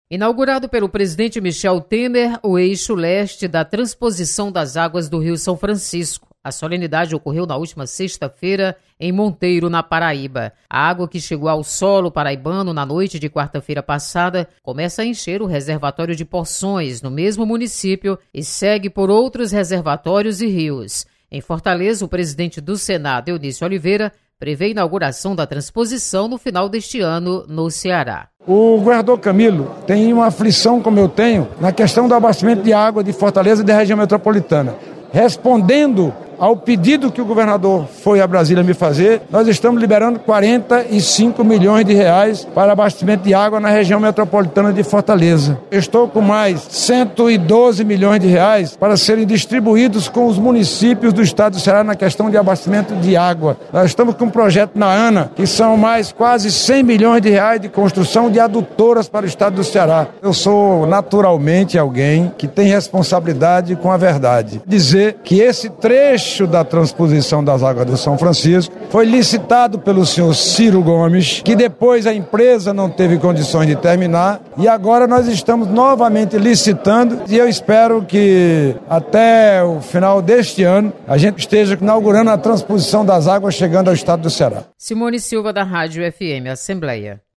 Você está aqui: Início Comunicação Rádio FM Assembleia Notícias Transposição